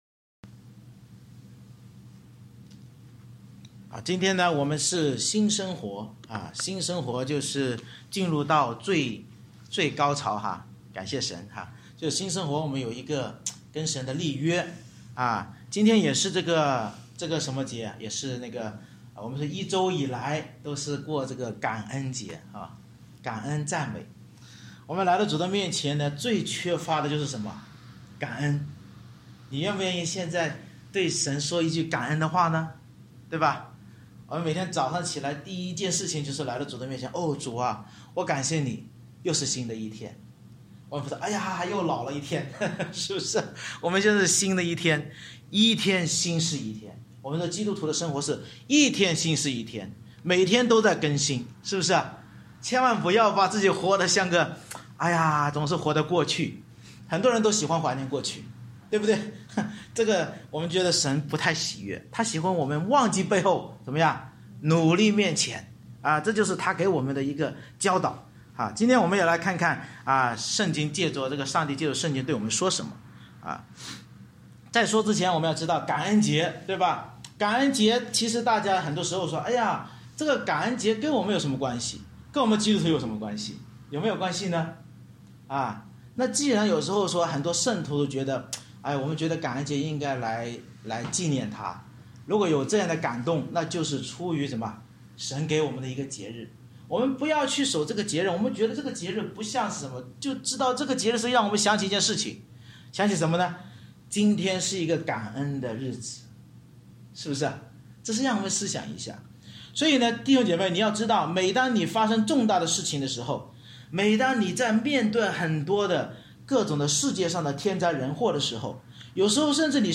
出埃及记24章 Service Type: 感恩主日 Bible Text